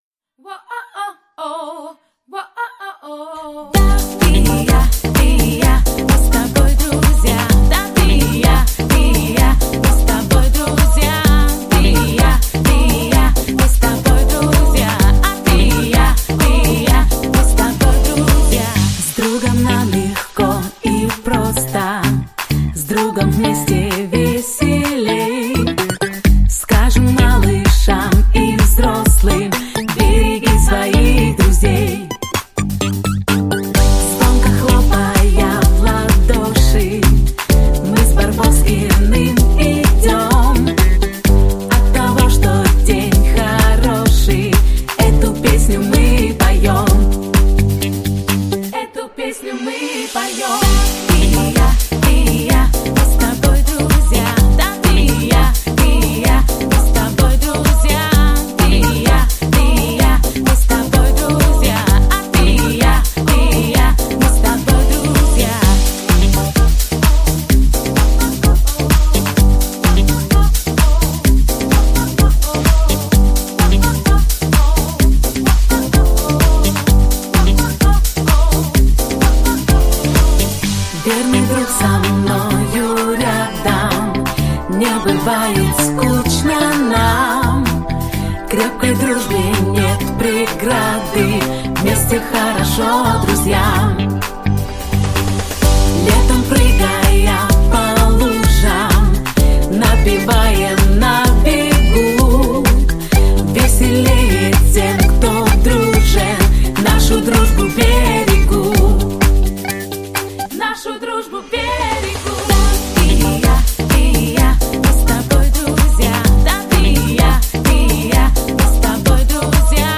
Песенка динамичная